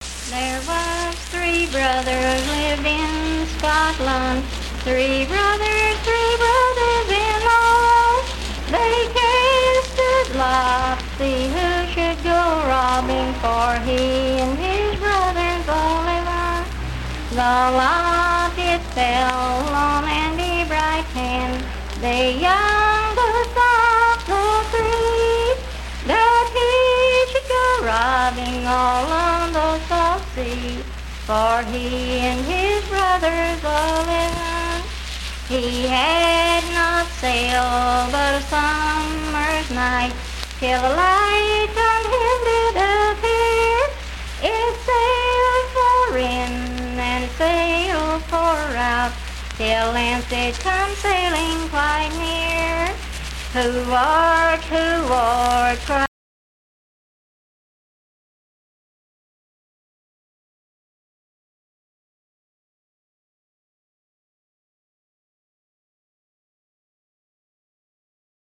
Unaccompanied vocal music
Voice (sung)
Spencer (W. Va.), Roane County (W. Va.)